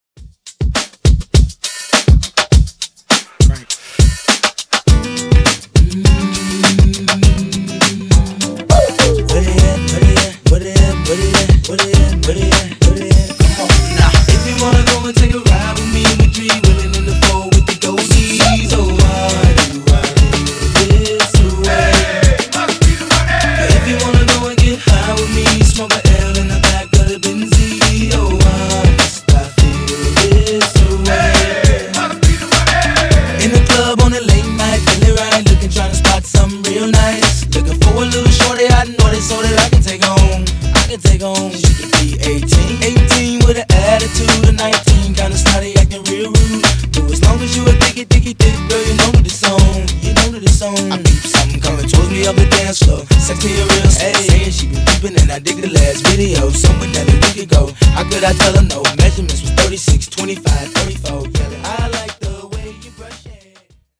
Genre: RE-DRUM
Clean BPM: 130 Time